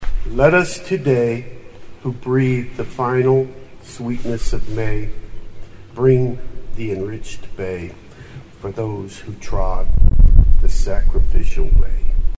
American Legion Post 17 hosted its annual Memorial Day ceremony in Sunset Cemetery on Monday, honoring those who had died in the fight for freedom.